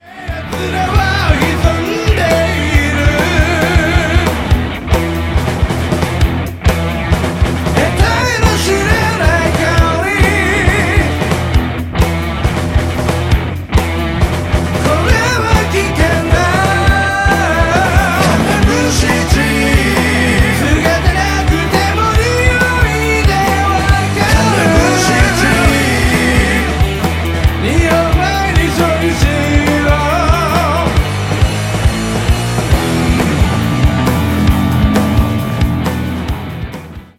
ロックバンド
ちょっぴりいねよな土着ロック 聴いてみませんか？